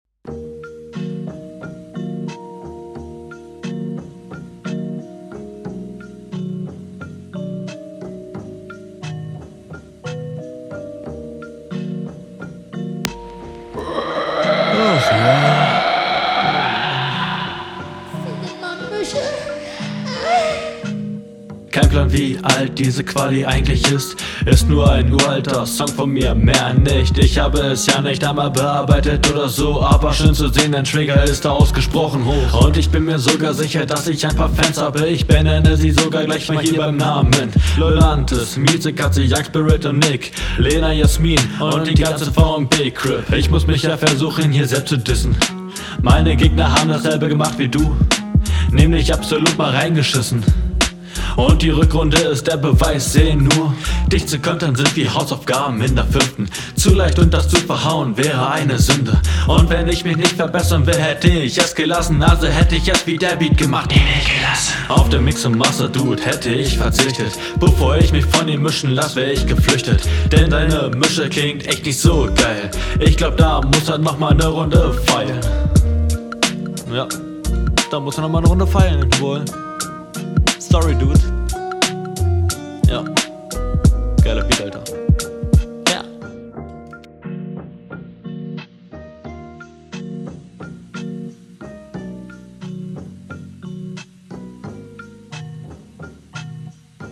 Flow: deutlich schwächer und angesträngter als der Gegner Text: das sind nicht deine Fans, dass …